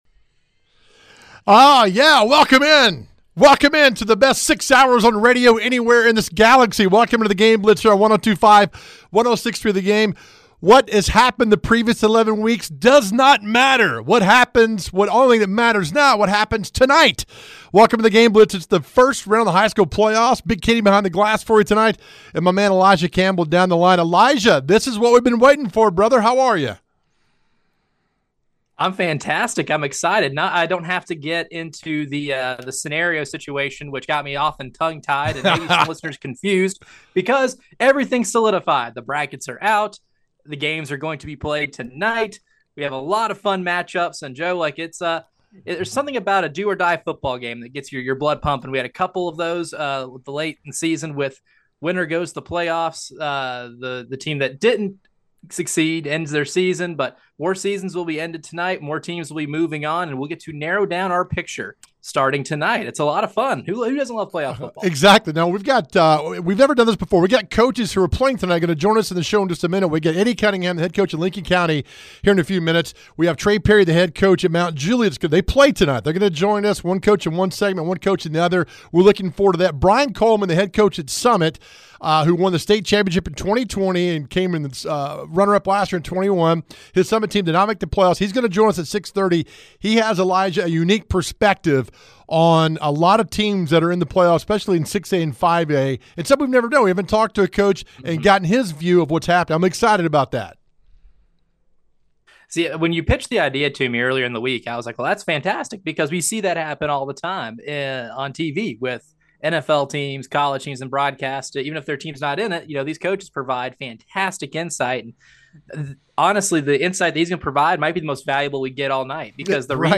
Its playoff time and we have full coverage for you right here with coaches interviews and live game reports as it happens!